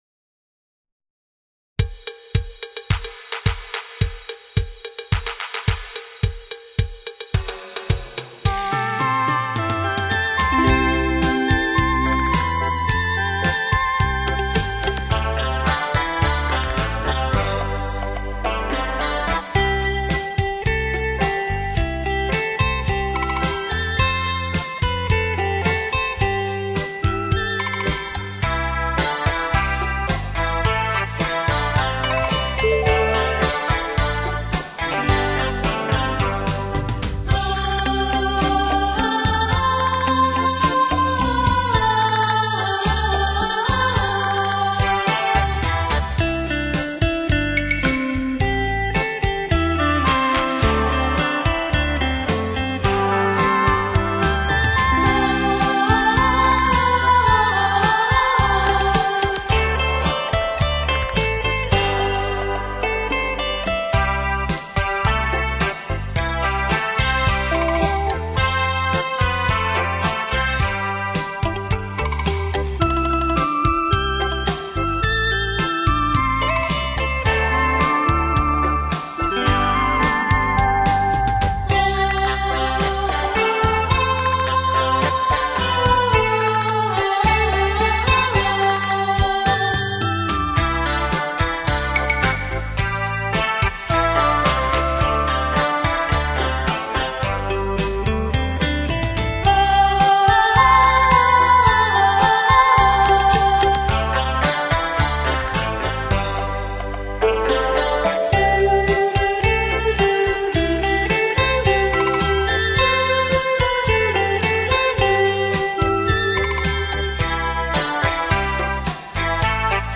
精心打造完美电音
电子技术融入到纯美的音乐当中
旋律美妙
独具韵味